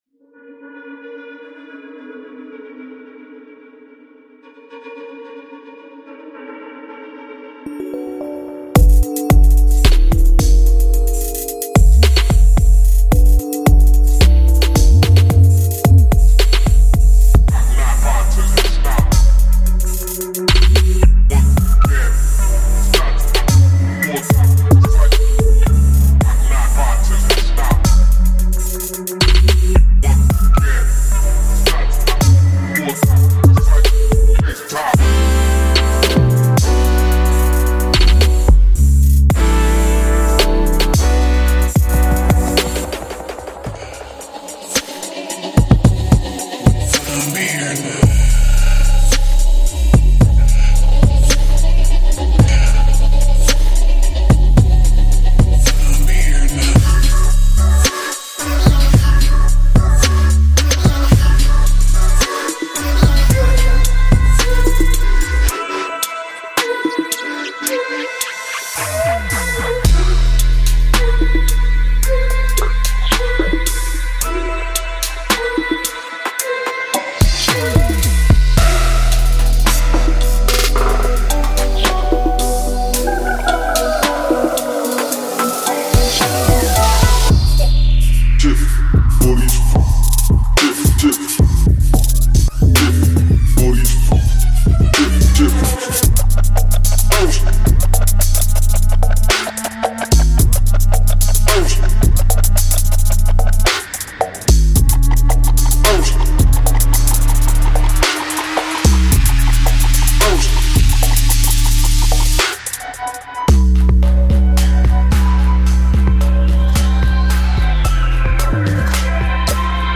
挤满了24位混合foley鼓循环，不寻常的人声排骨，空灵的弹奏，坚韧的808音和闻所未闻的fx循环。
•68个鼓循环
•23个低音循环
•40个旋律循环
•22个FX和人声循环